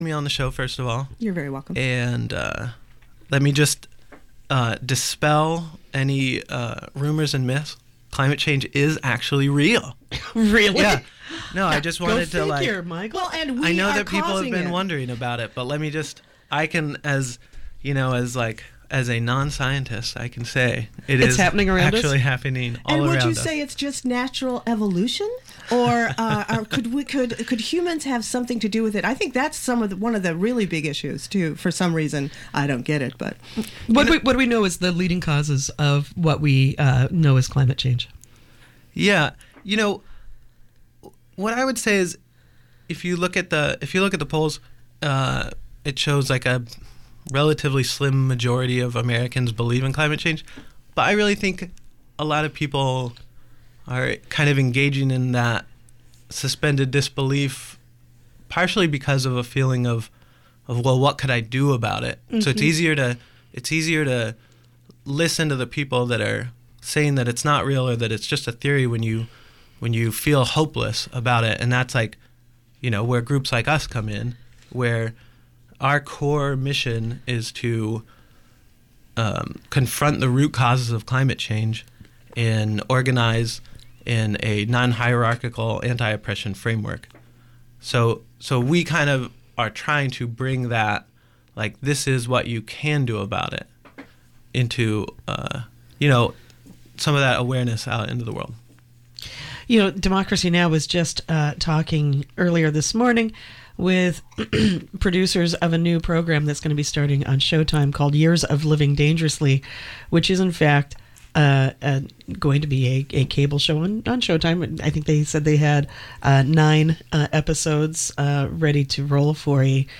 live in the studio to take your calls about negotiating a fair deal from clients, protecting your creative work